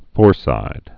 (fôrsīd)